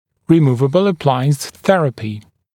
[rɪ’muːvəbl ə’plaɪəns ‘θerəpɪ][ри’му:вэбл э’плайэнс ‘сэрэпи]терапия с применением съемных аппаратов